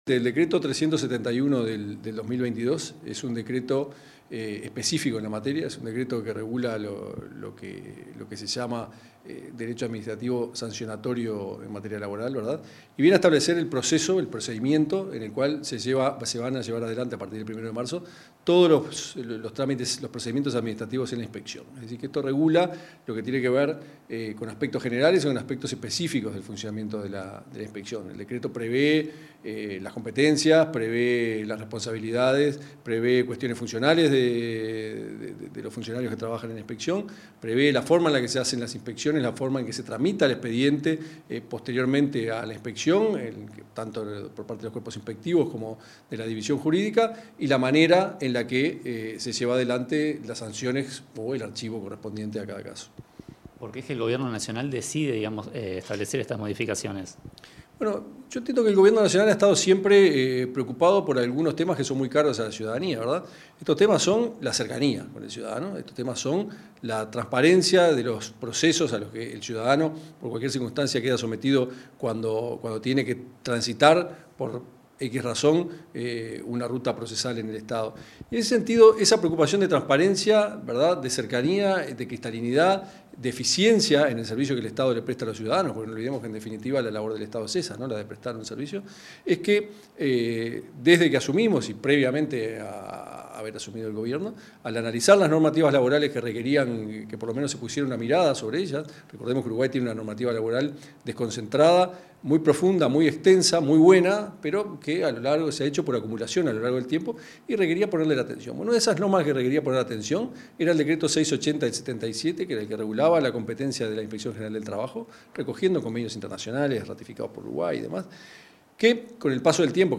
Entrevista al inspector de Trabajo del MTSS, Tomás Teijeiro
El inspector de Trabajo del Ministerio de Trabajo y Seguridad Social (MTSS), Tomás Teijeiro, en declaraciones a Comunicación Presidencial, este 8 de